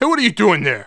1 channel
welder-donttouch1.wav